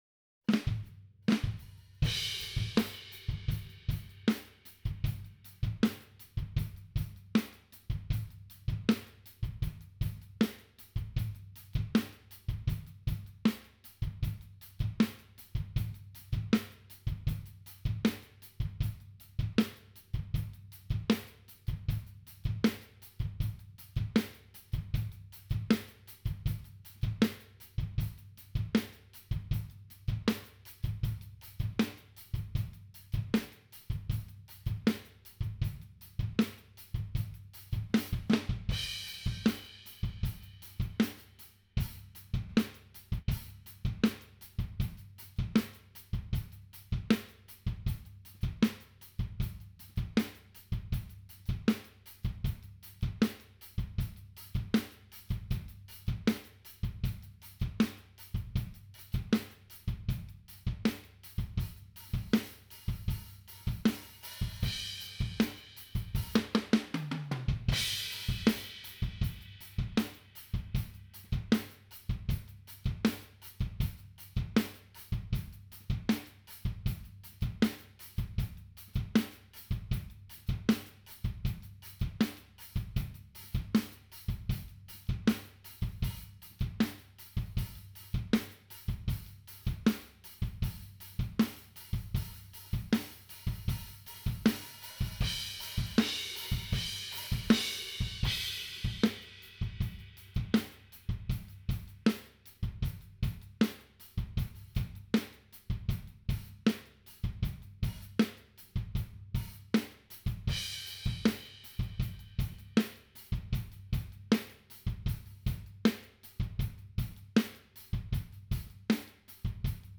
hardraindrums.wav